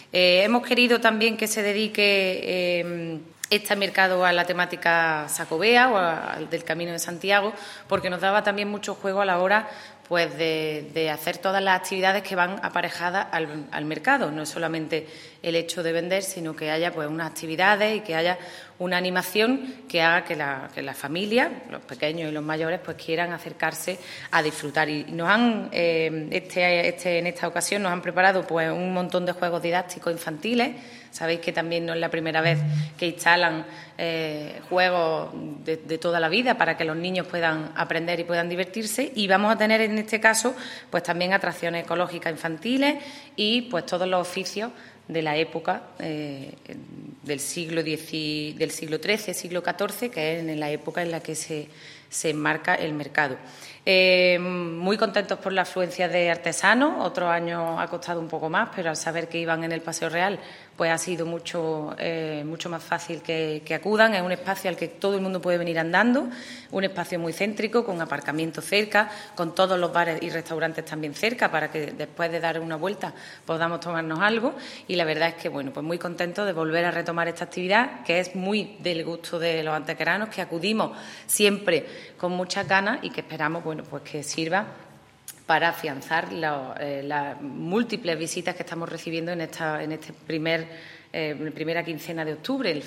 La teniente de alcalde delegada de Turismo, Patrimonio Histórico, Políticas de Empleo y Comercio, Ana Cebrián, ha presentado una nueva edición de una popular iniciativa que llevaba sin poder realizarse desde el año 2019 como consecuencia de la pandemia. Se trata del mercado temático, ambientado este año en el Camino de Santiago con el sobrenombre de "Mercado del Peregrino".
Cortes de voz